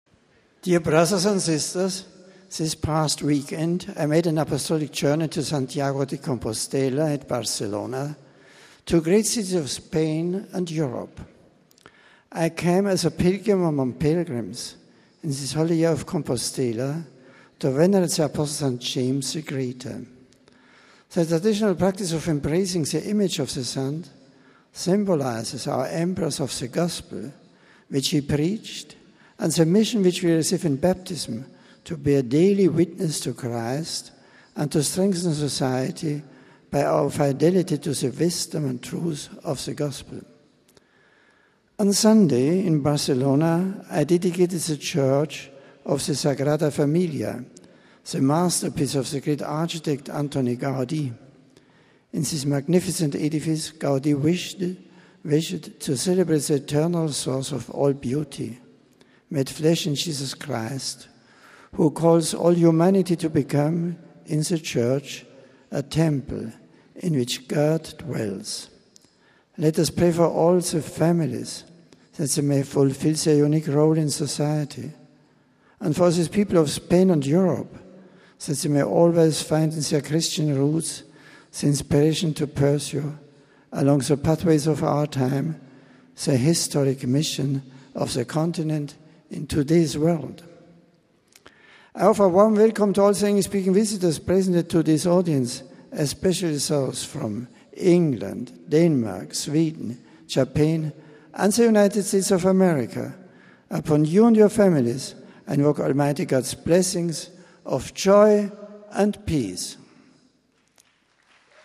(November 10, 2010) Every week on Wednesday, the Pope holds a public meeting, called the general audience, during which pilgrims and tourists who come to Rome have a chance of seeing and hearing him speak in several languages.
After that, in the Vatican’s Paul VI audience hall he met the rest. This week, Pope Benedict reviewed his visit to Santiago de Compostela and Barcelona in Spain, last weekend.
Pope Benedict also spoke in several other languages before concluding Wednesday’s audience with his blessing.